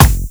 KICKSTACK1-R.wav